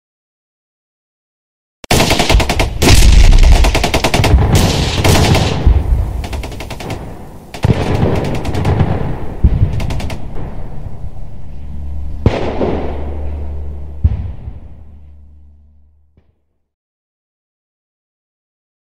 Download Free War Zone Sound Effects